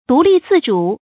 注音：ㄉㄨˊ ㄌㄧˋ ㄗㄧˋ ㄓㄨˇ
獨立自主的讀法